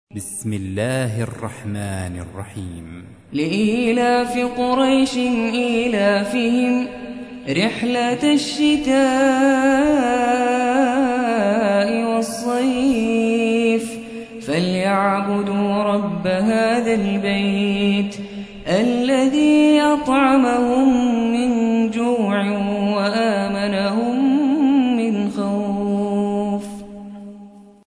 106. سورة قريش / القارئ